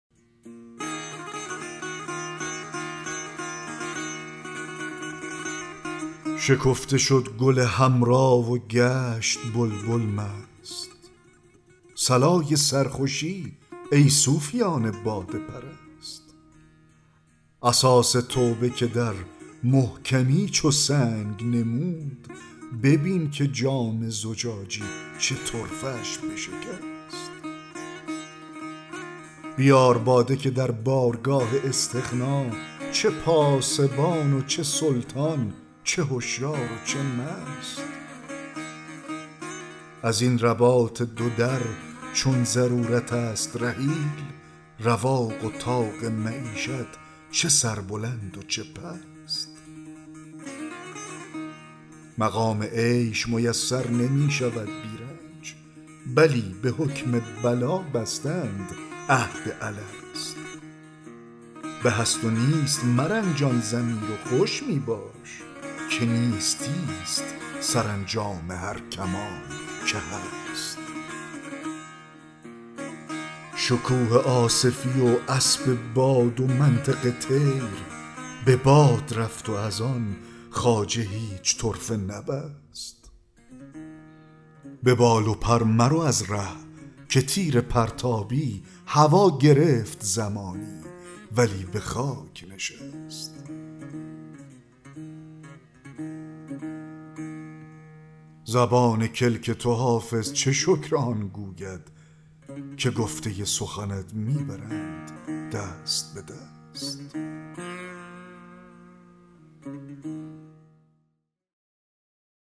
دکلمه غزل 25 حافظ
دکلمه-غزل-25-حافظ-شکفته-شد-گل-حمرا-و-گشت-بلبل-مست.mp3